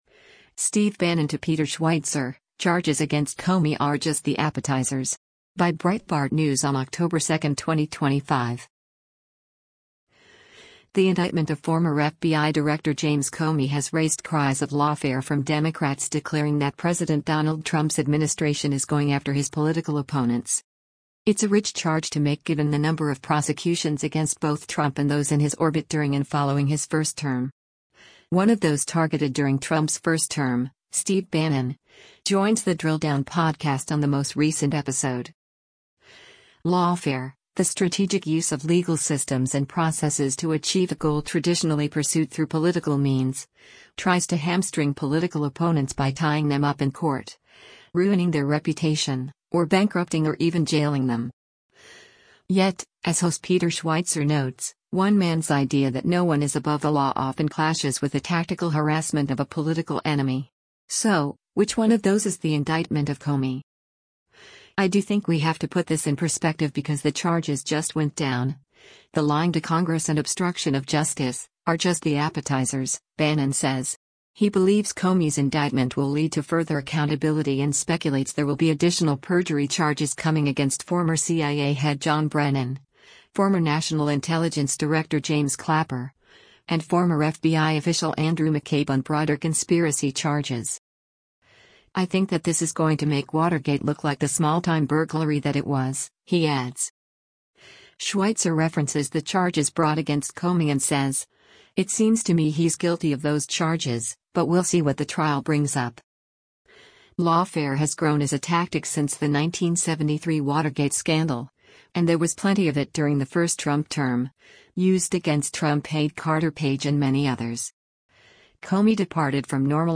One of those targeted during Trump’s first term, Steve Bannon, joins The Drill Down podcast on the most recent episode.